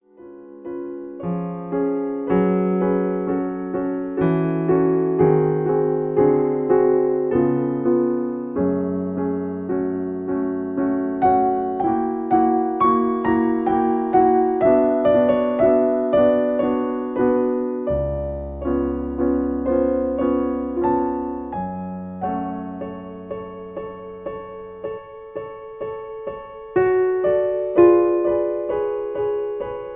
In F sharp. Piano Accompaniment